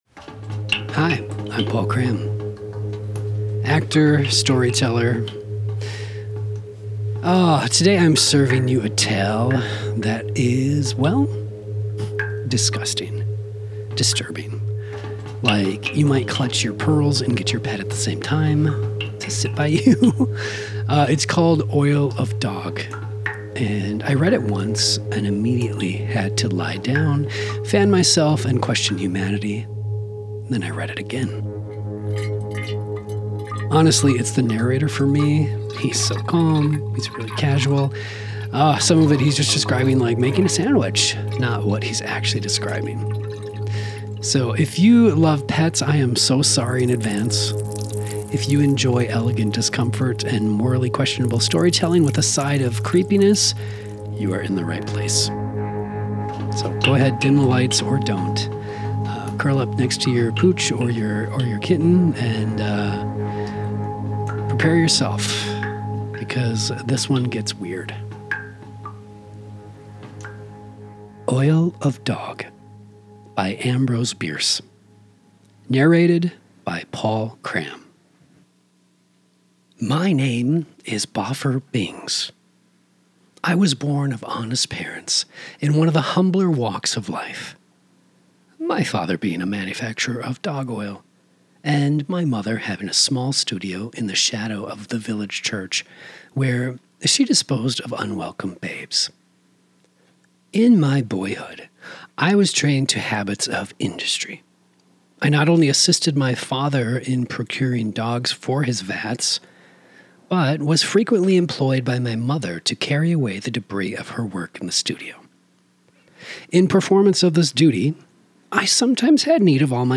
A disturbingly calm narrator, a questionable family business, and one very bad day for man’s best friend.